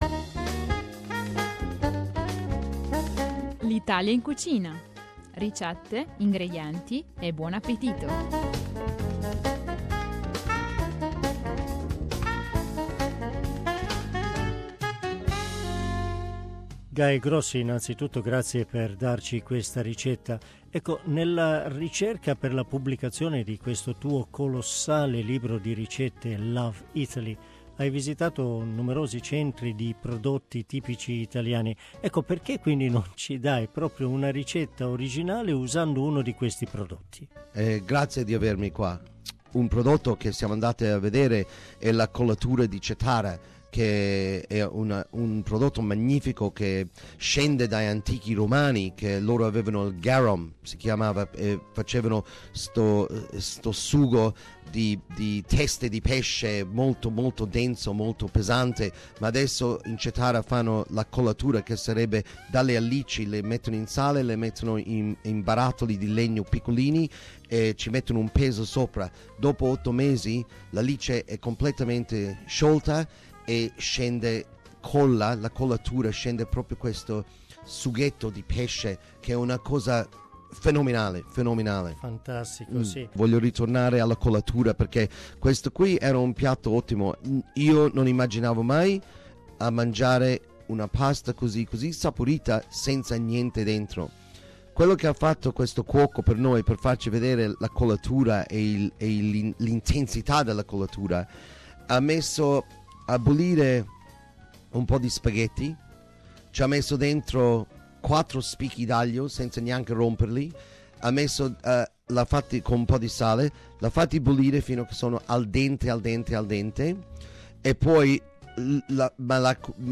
This week's recipe is presented by Guy Grossi, one of the most well-known Australian chefs of Italian origin.